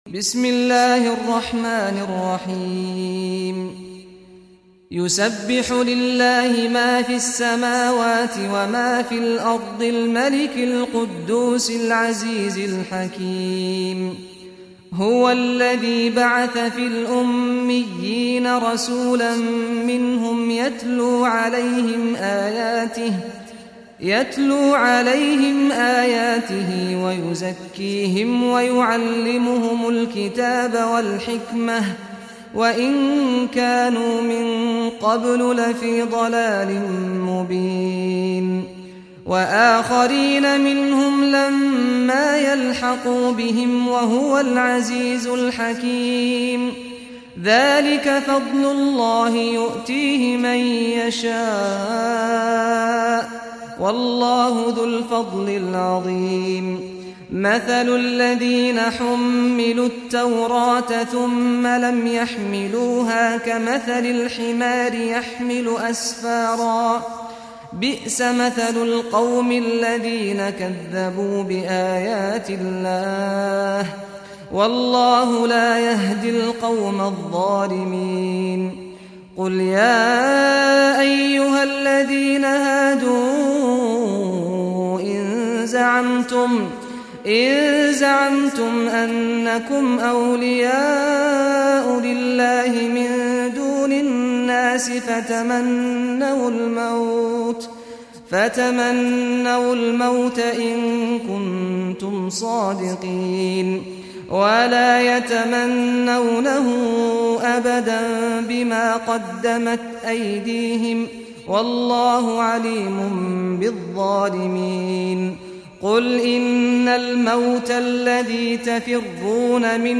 • Category: Muslim prayer